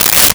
Paper Tear 02
Paper Tear 02.wav